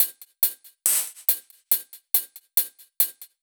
Index of /musicradar/french-house-chillout-samples/140bpm/Beats
FHC_BeatB_140-02_Hats.wav